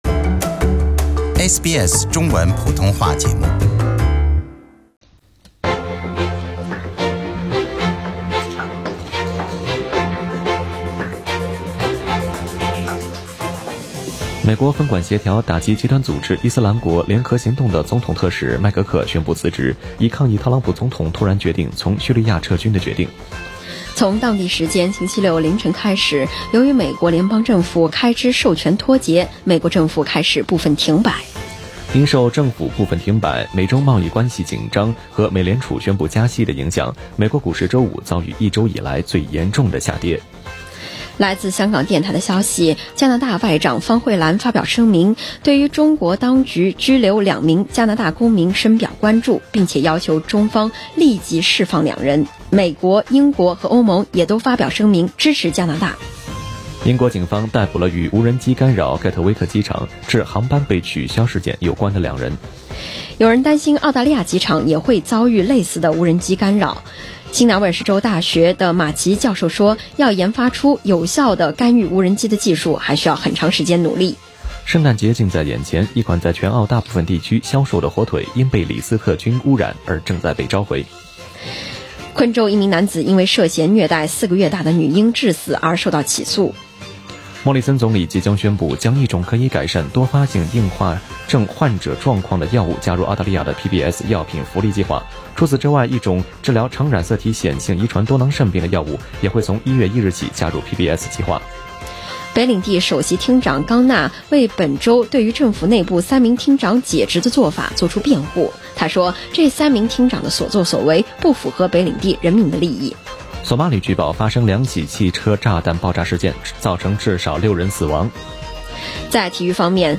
SBS早新闻 （12月23日）